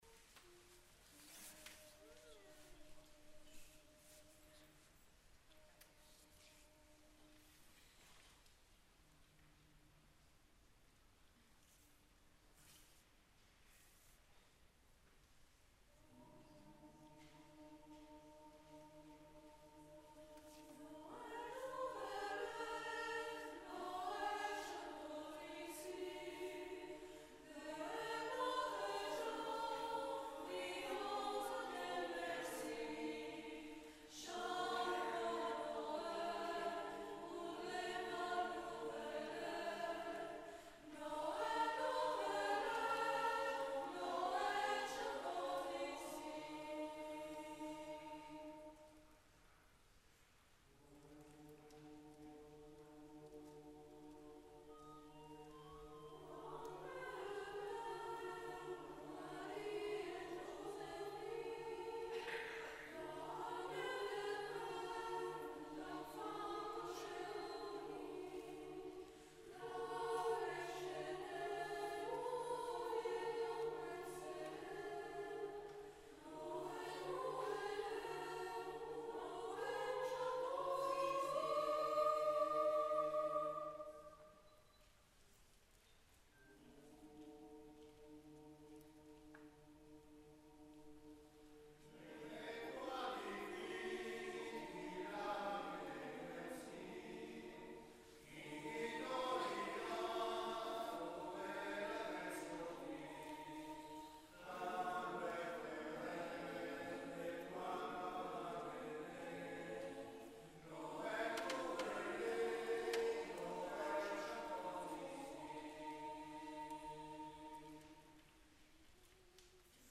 Programma di canti natalizi polifonici gospel & spirituals
presso a Cappella dell'ospedale "Regina Apostolorum"
tradizionale francese